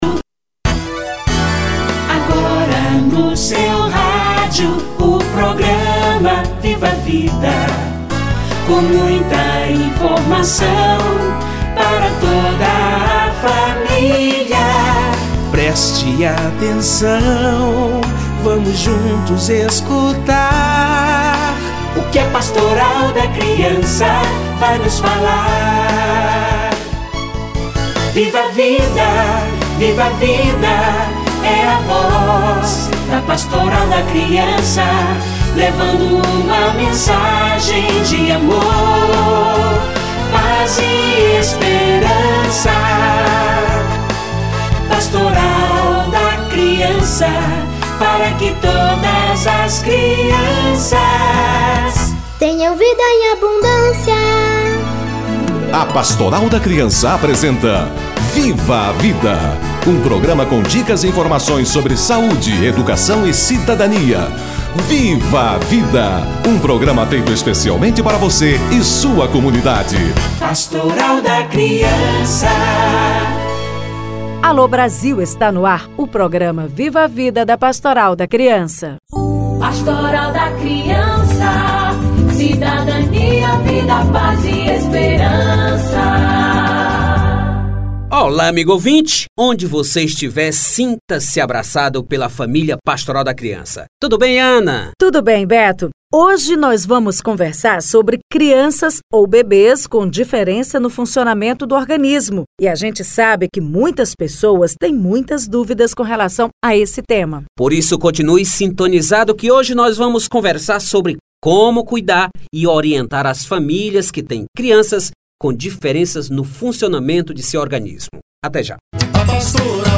Criança com diferença no funcionamento do seu organismo - Entrevista